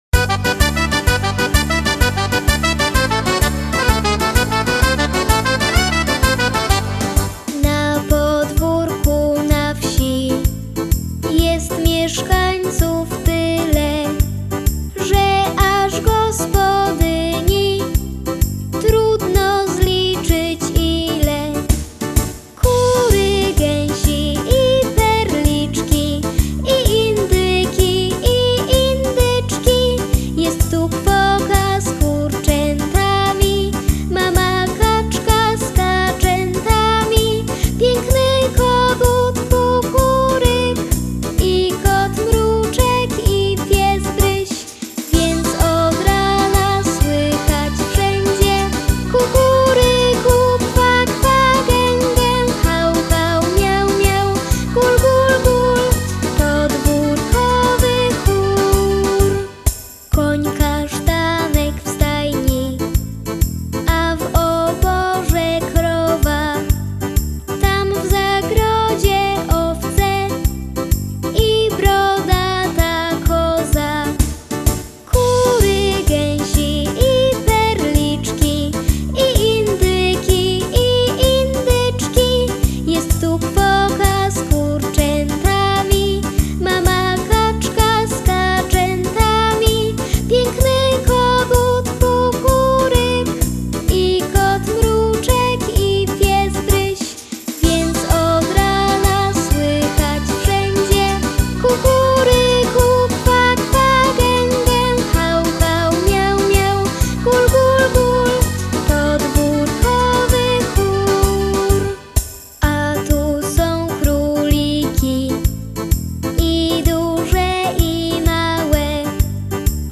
4-5 –latki Piosenka „ Na Podwórku”